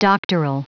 Prononciation du mot doctoral en anglais (fichier audio)